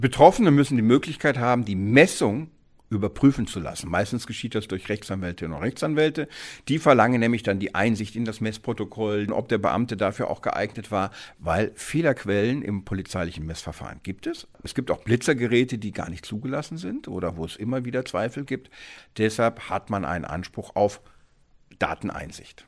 O-Ton: Nach dem Blitzen müssen Behörden Mess-Protokolle herausgeben – Vorabs Medienproduktion